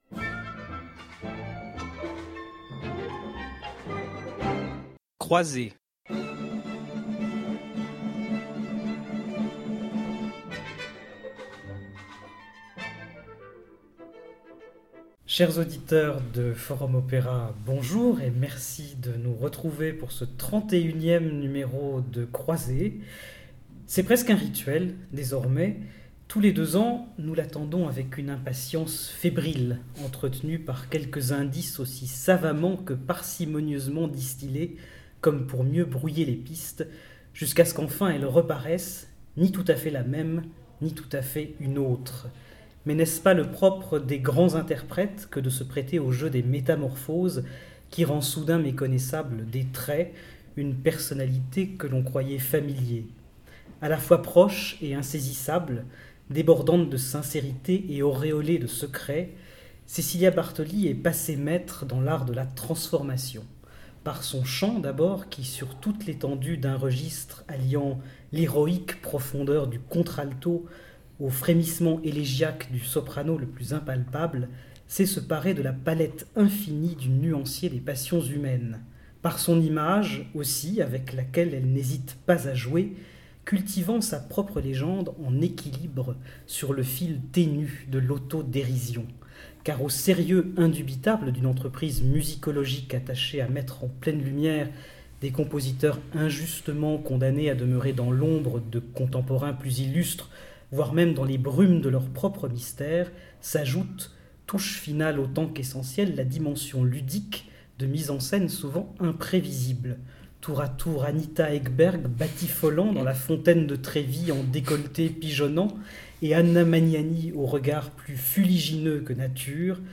Émission enregistrée chez Rolex (Paris) le 14 octobre 2014.